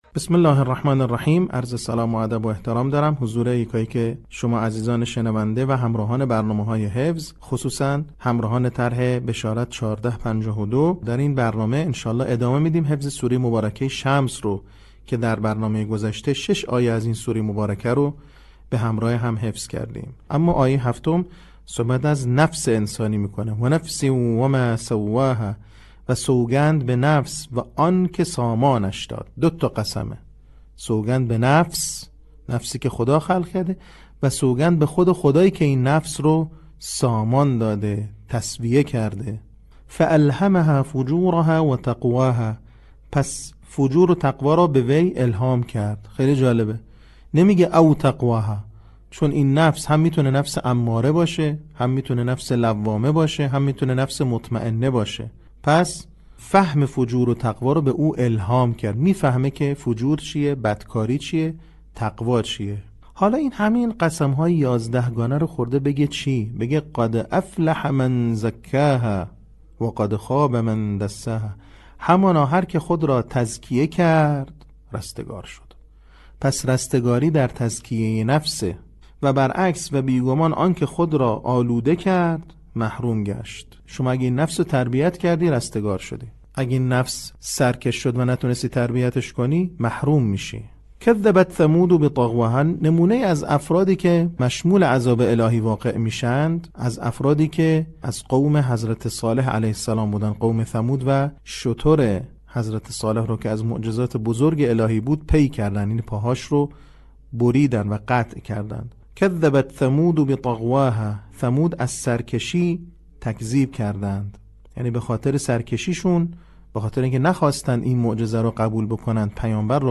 صوت | بخش دوم آموزش حفظ سوره شمس